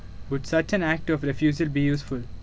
Phonetically Rich Audio Visual (PRAV) corpus
a2302_M2.wav